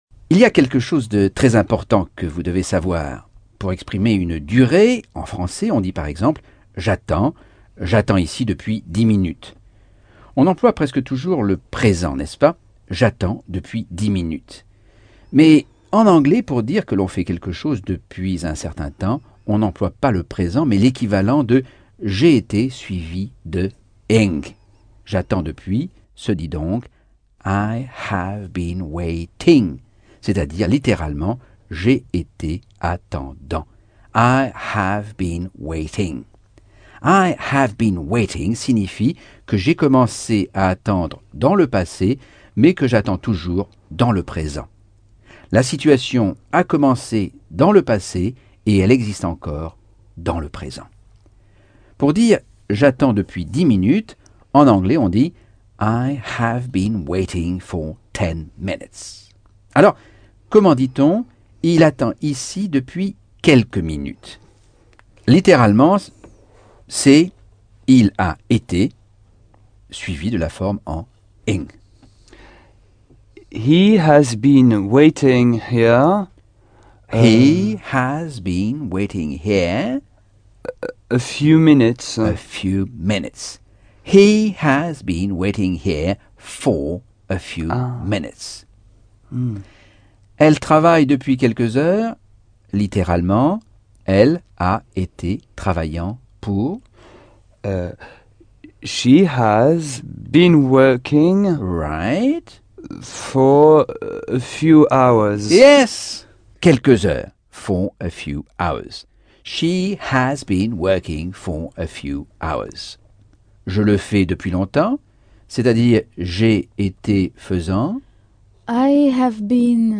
Leçon 2 - Cours audio Anglais par Michel Thomas - Chapitre 9